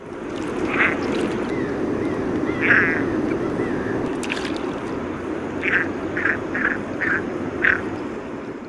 Red-breasted Merganser
Mergus serrator